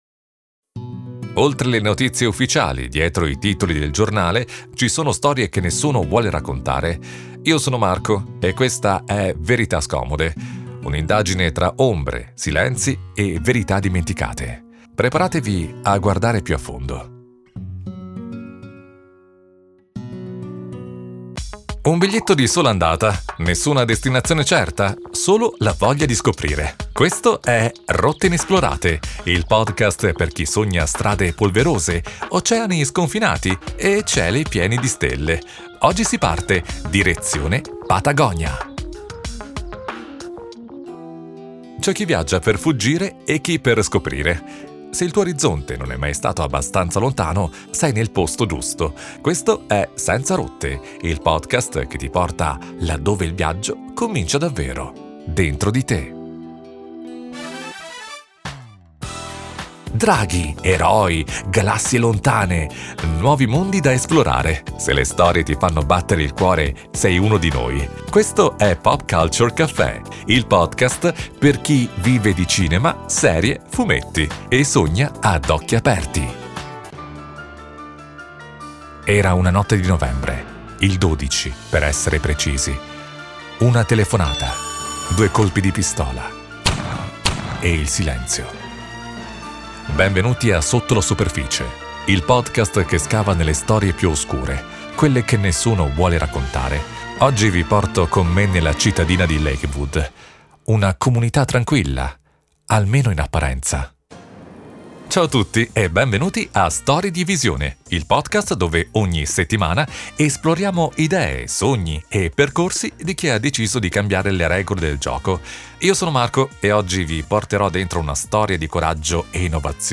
My voice is very versatile, warm, conversational, and real.